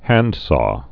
(hănd)